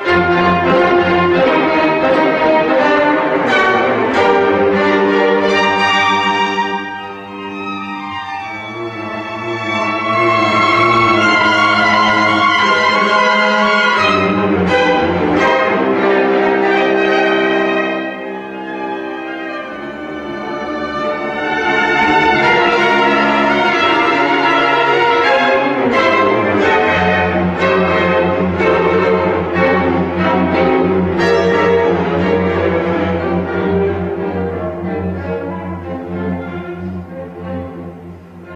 론도의 세 번째 섹션은 복잡하고, 셋잇단음표와 헤미올라의 색채적인 소용돌이가 있는 섹션으로, 청취자가 첫 박에 대한 감각을 모두 잃게 만든다.
세 번째 섹션: 복잡한 화음, 여린 박 리듬